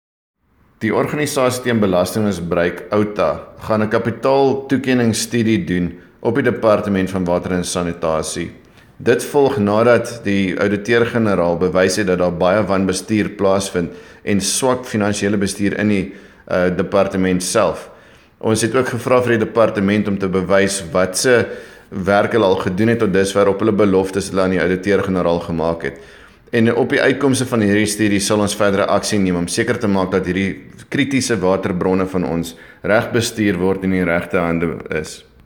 Afrikaans Sound Bite